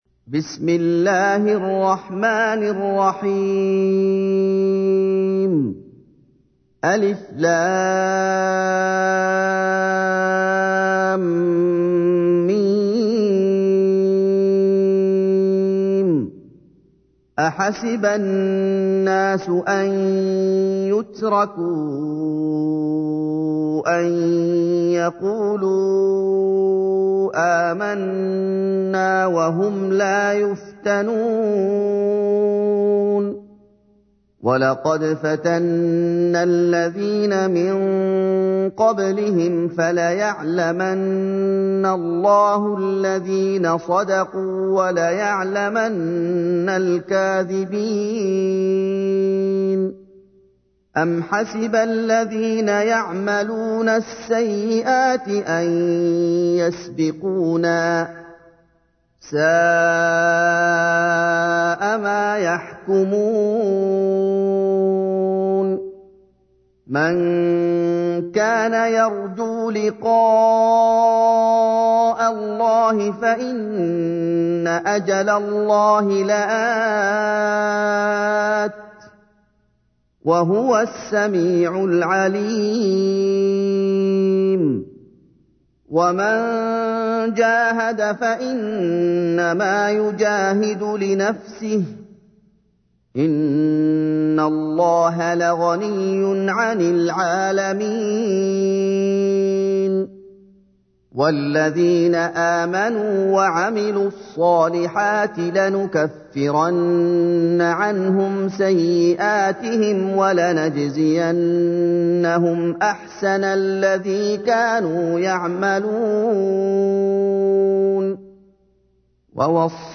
تحميل : 29. سورة العنكبوت / القارئ محمد أيوب / القرآن الكريم / موقع يا حسين